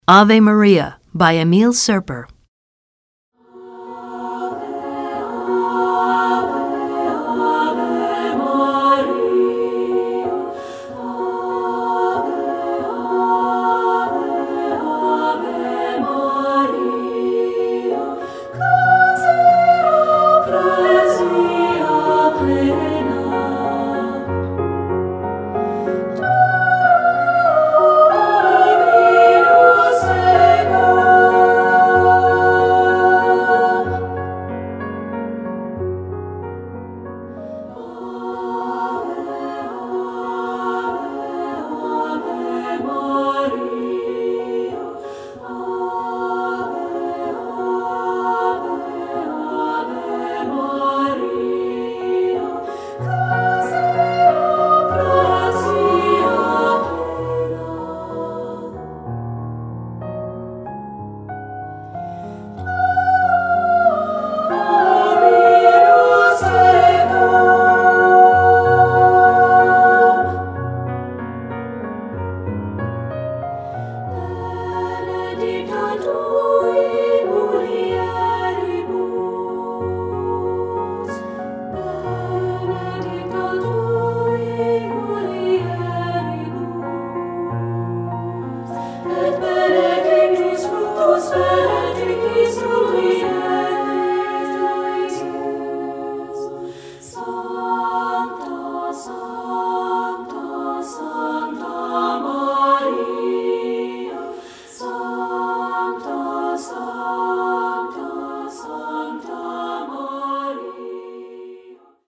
Répertoire pour Chant/vocal/choeur - 3 Parties Mixtes